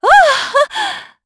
Isolet-Vox_Happy4_kr.wav